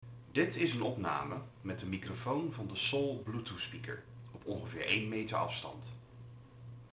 Als laatste kunnen we in de praktijk nog even luisteren naar de kwaliteit van de microfoon op één meter afstand. Bij de eerste opname is de voorkant van de Soul naar mij gericht en bij de tweede opname is de achterkant naar mij gericht.
Om er direct in te duiken: de kwaliteit is logischerwijs niet denderend.
Microfoonopname-Fresh-n-Rebel-Soul-voorkant.mp3